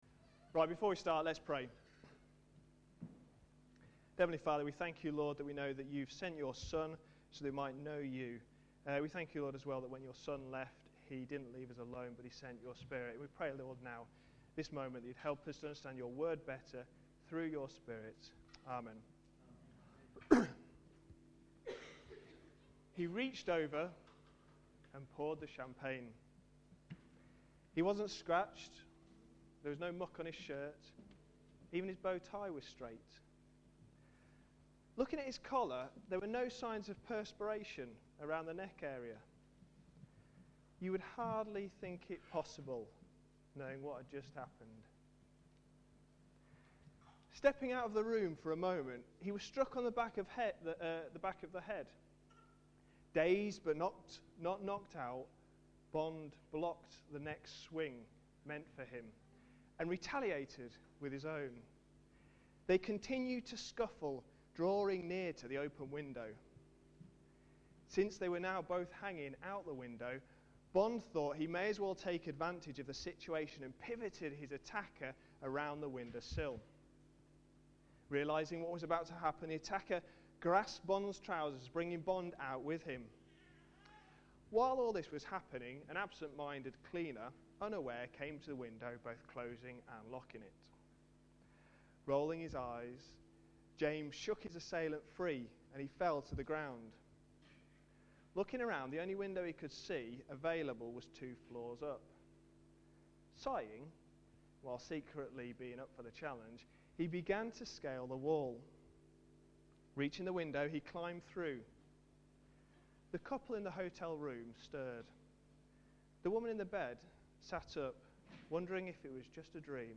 A sermon preached on 16th January, 2011, as part of our What does the Bible say about... series.